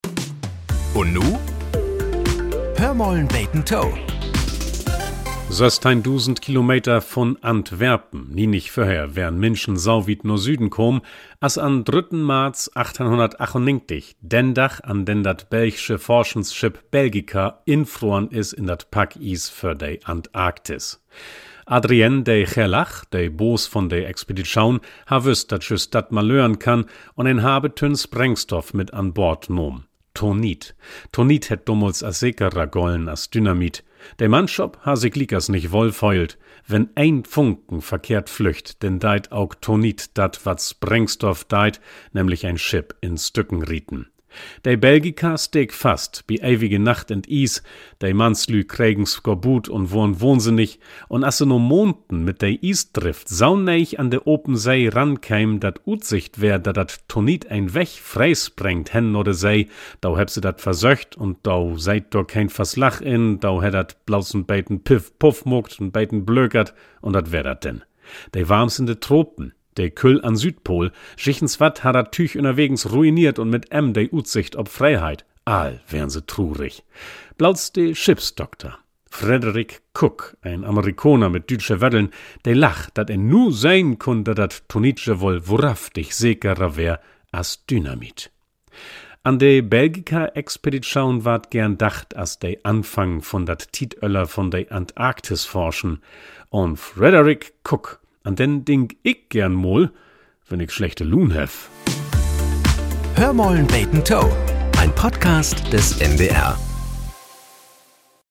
Nachrichten - 15.02.2025